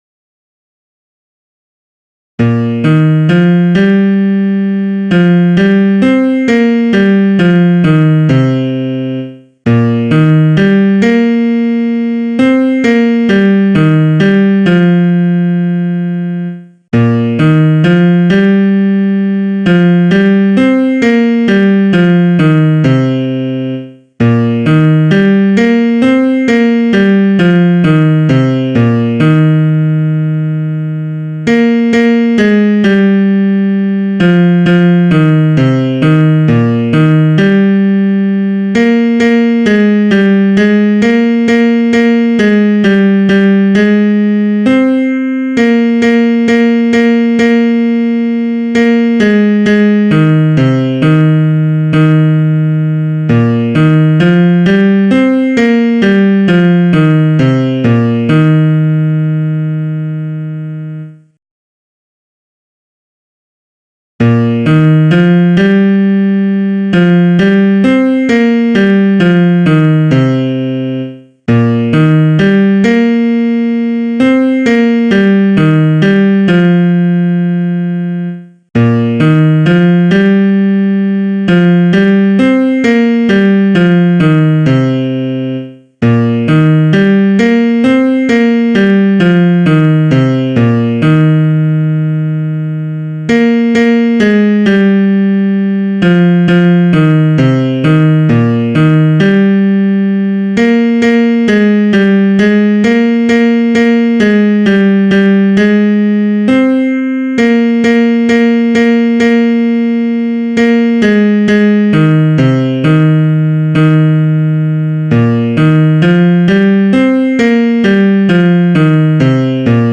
Baryton